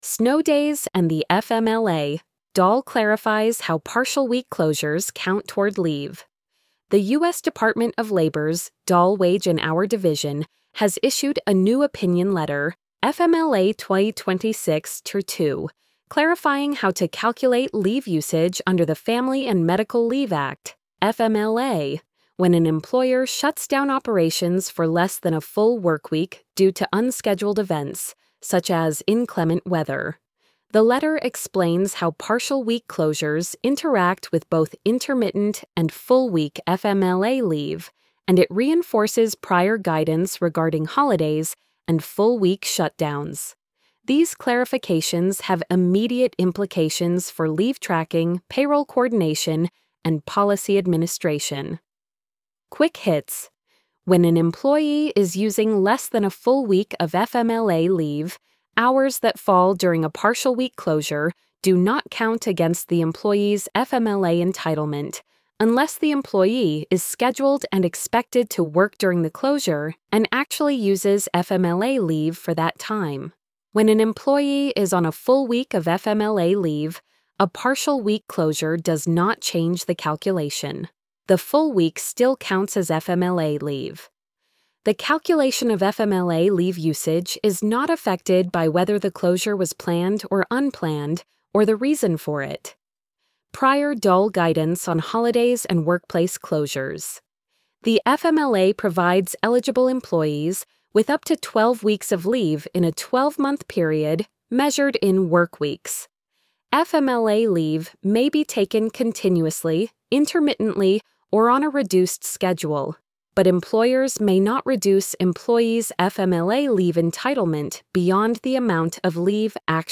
snow-days-and-the-fmla-dol-clarifies-how-partial-week-closures-count-toward-leave-tts.mp3